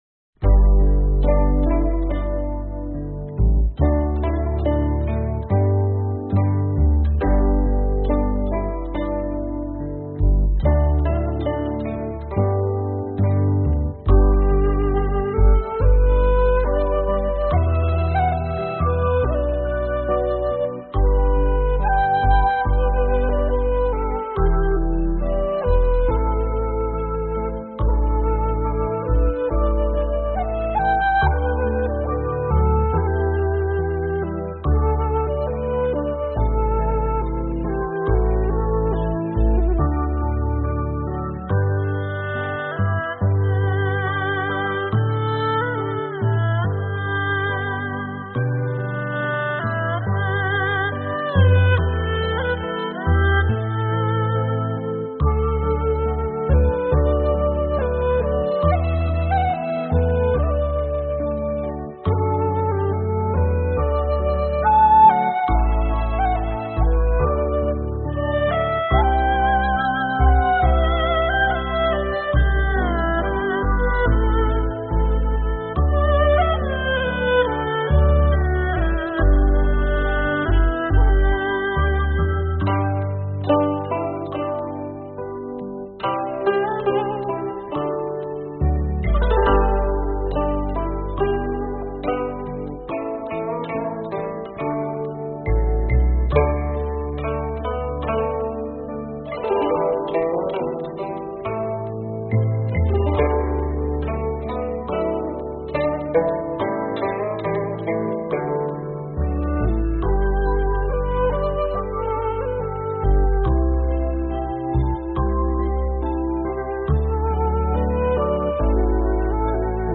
中段柳琴與竹笛交替出現，給人一種香 風拂面的感覺。